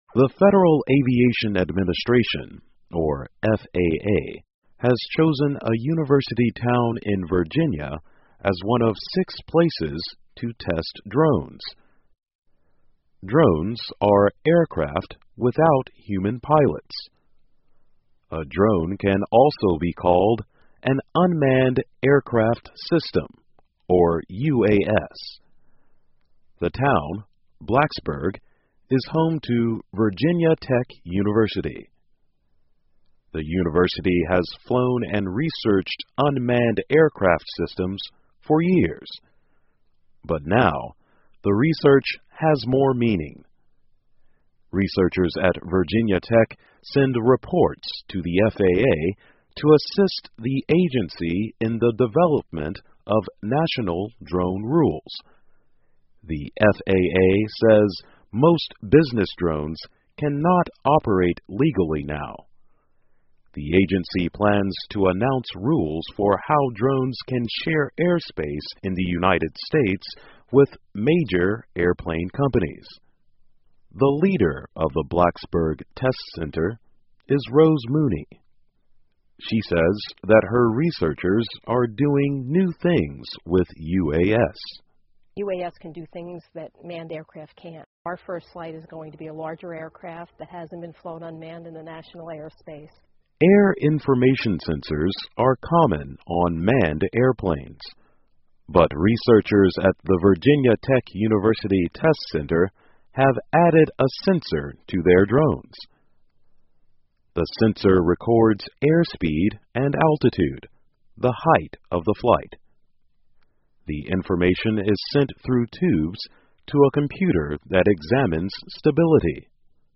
VOA慢速英语2014 维吉尼亚测试无人机以帮助航空管理局制定发展规则 听力文件下载—在线英语听力室